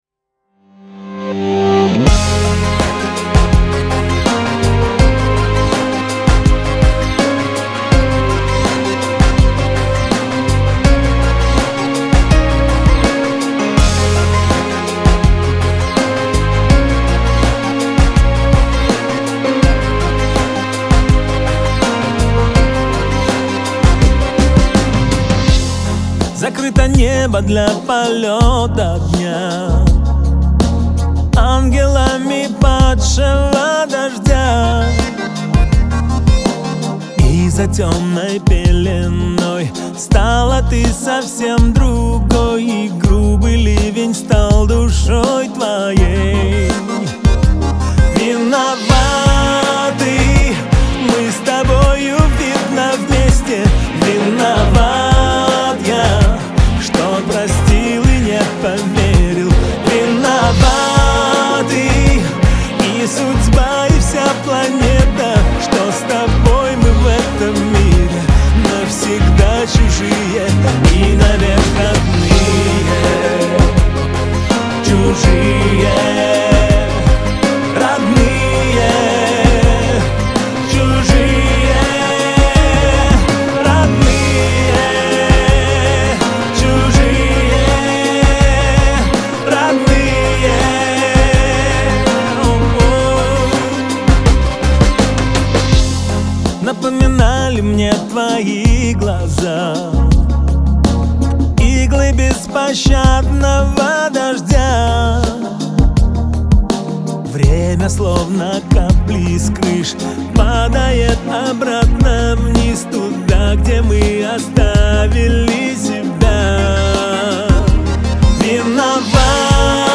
это глубокое и трогательное произведение в жанре поп-рок